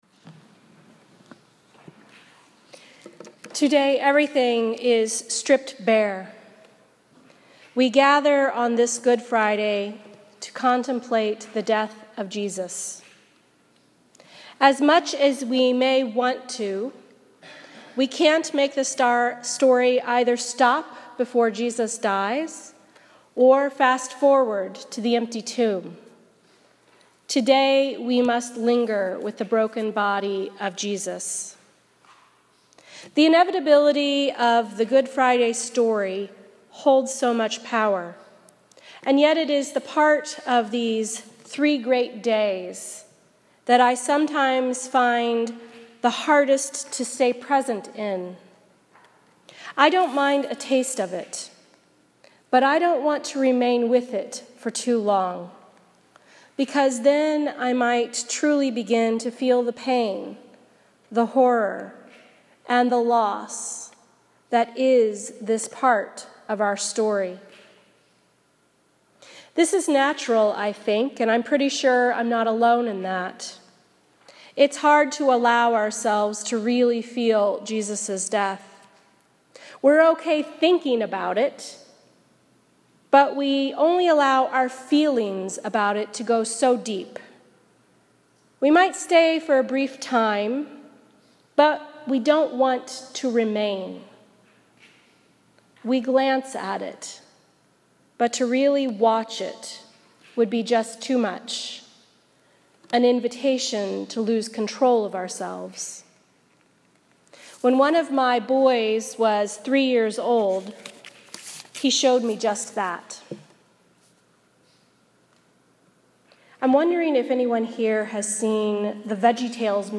A Sermon for Good Friday 2016